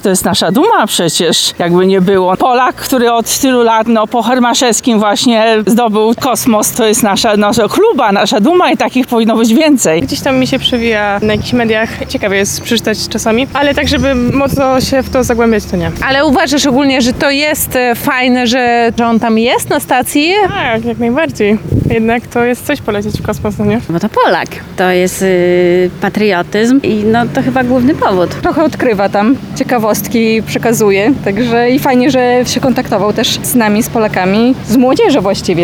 – To niezwykle wydarzenie – mówią mieszkańcy Lublina.